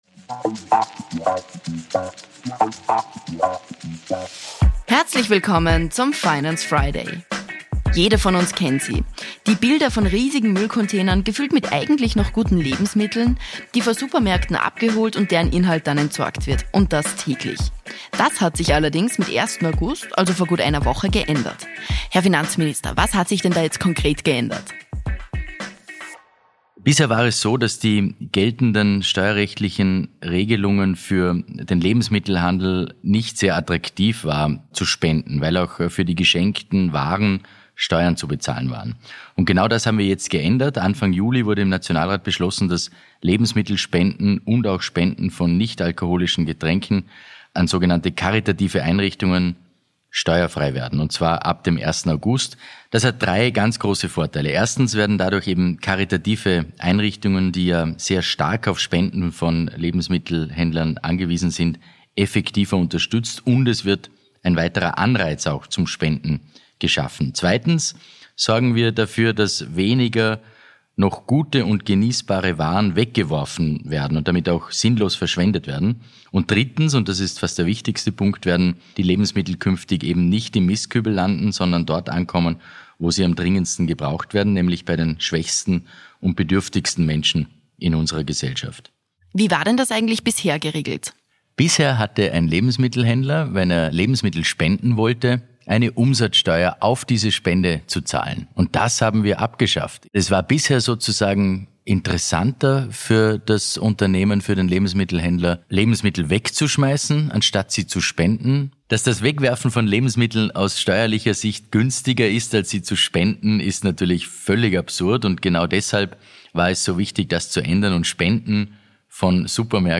„Finance Friday“ erklärt Finanzminister Magnus Brunner, was das für